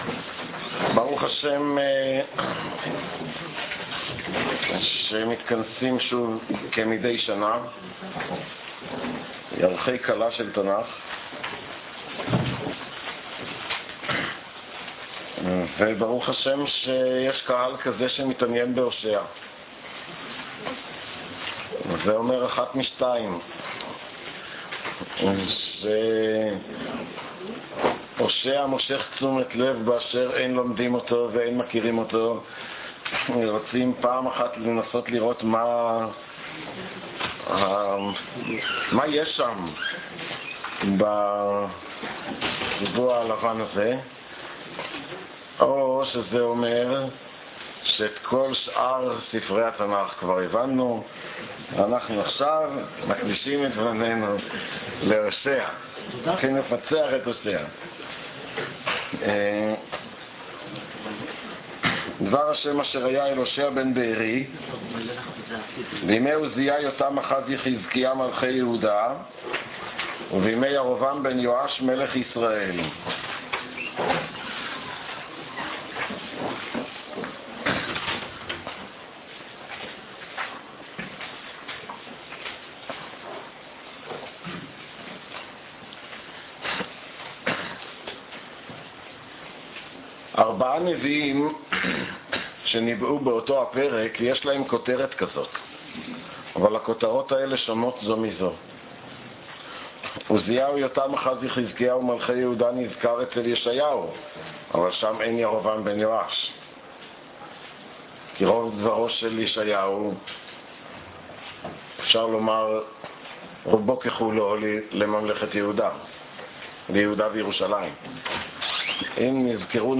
שיעור זה מימי העיון בתנ"ך תשס"ו.